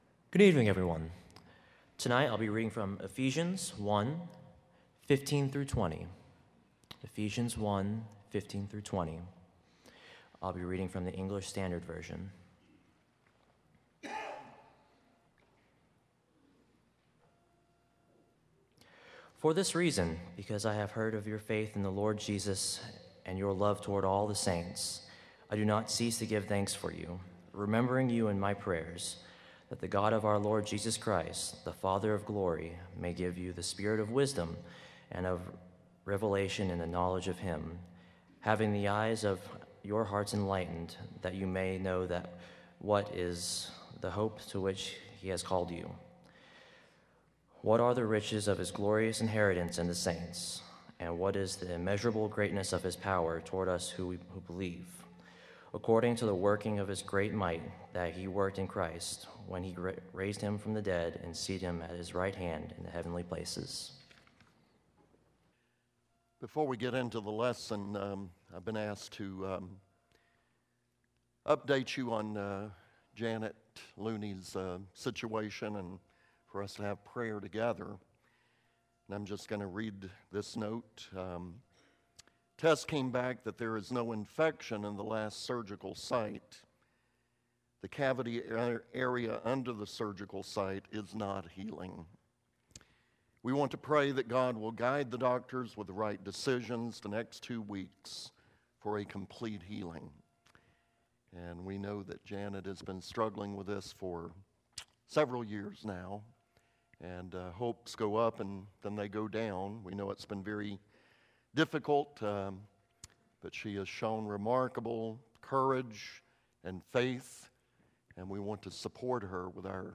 Proverbs 15:29 (English Standard Version) Series: Sunday PM Service